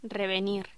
Locución: Revenir
voz
Sonidos: Voz humana